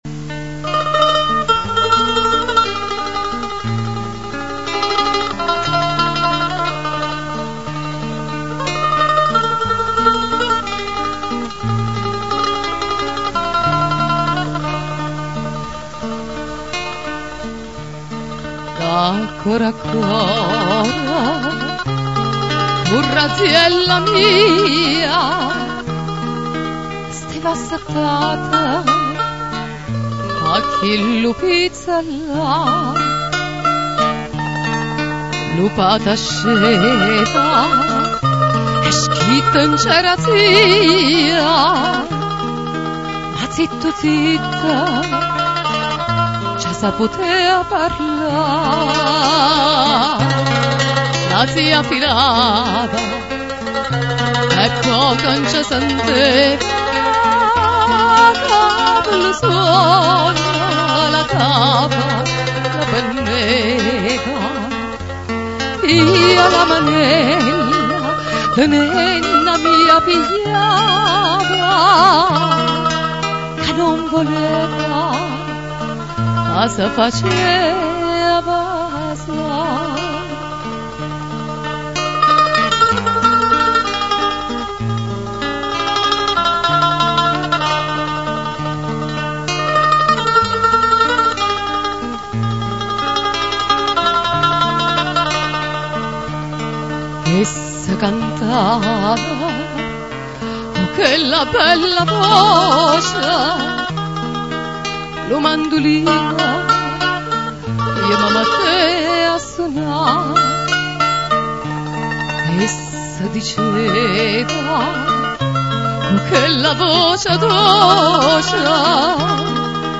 Ascolta Classiche vesuviane cantate da " Napoli Antica" Se non si ascolta subito il suono attendere qualche secondo, solo la prima volta